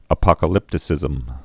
(ə-pŏkə-lĭptĭ-sĭzəm)